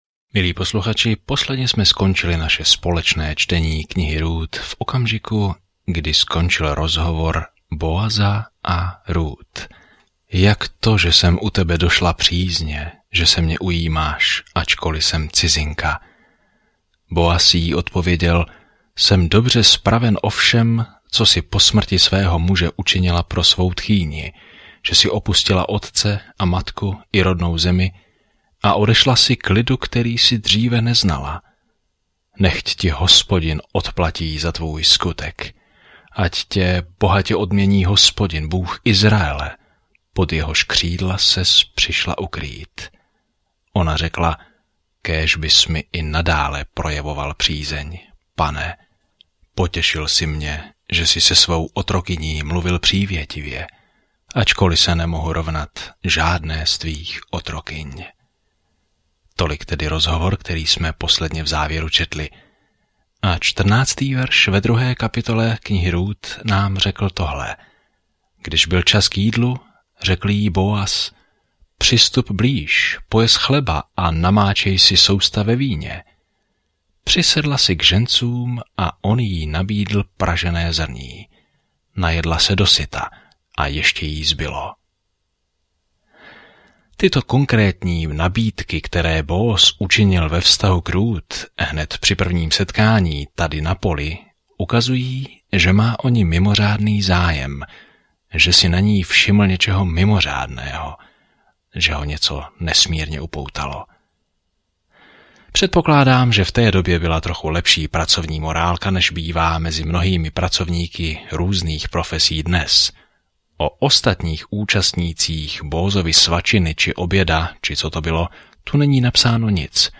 Písmo Rút 2:15-23 Rút 3:1-2 Den 3 Začít tento plán Den 5 O tomto plánu Ruth, milostný příběh, který odráží Boží lásku k nám, popisuje dlouhý pohled na historii – včetně příběhu krále Davida… a dokonce i Ježíšova. Denně cestujte po Ruth a poslouchejte audiostudii a čtěte vybrané verše z Božího slova.